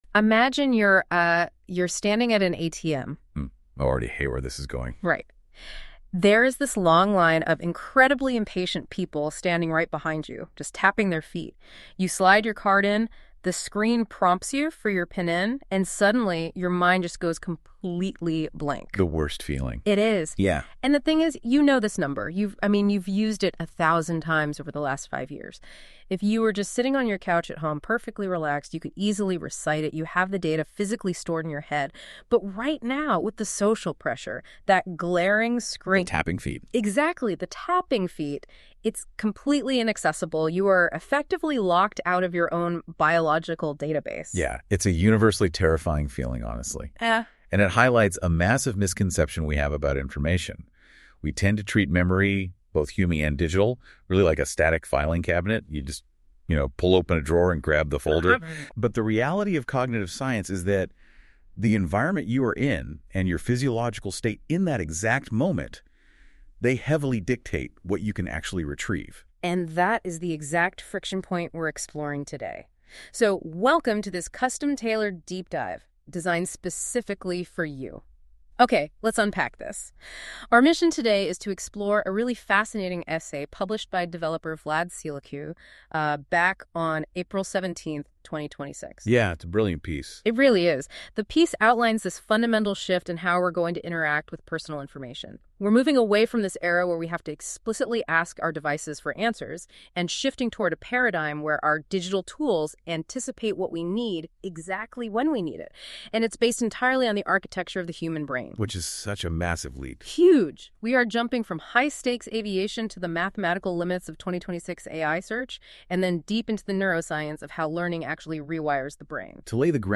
OFFLINE-READY NOTEBOOKLM AUDIO ▶ ❚❚ 00:00